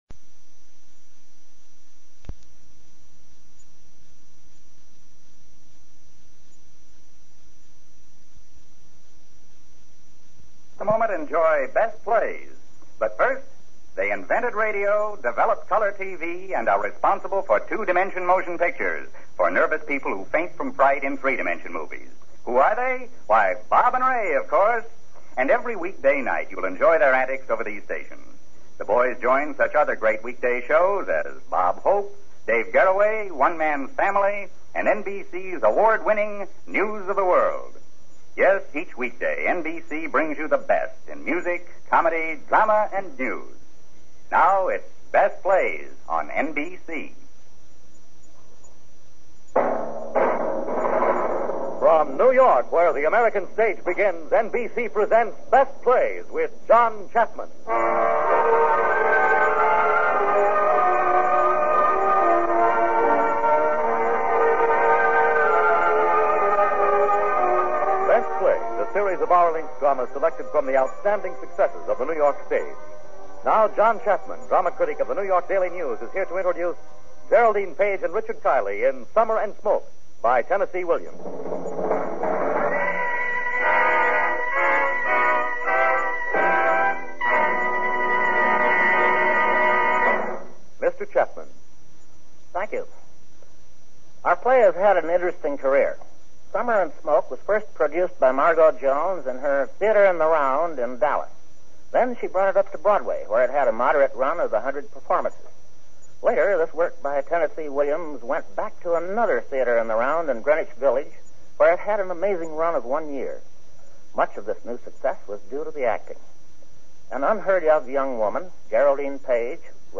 Going on-air from 1952 to 1953, the Best Plays was an NBC Radio program that featured some of the most excellent theatric plays ever created. Some of the best ones featured were dramatic or comedic plays.